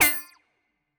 Futuristic Device Glitch (4).wav